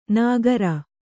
♪ nāgara